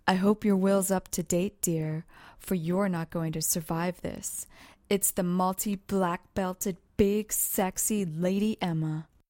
用AT2020话筒录入Apogee Duet.
Tag: 说话 说话 声带 声音 女人